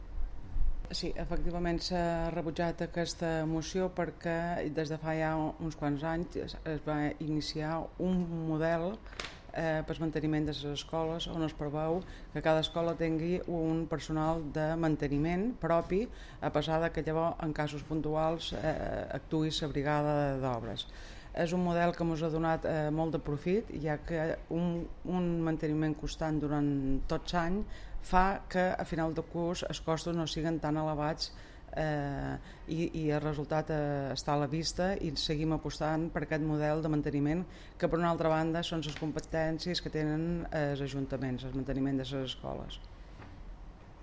DECLARACIONS (mp3)
Ana Costa (regidora d'Educació): Personal de manteniment dels col·legis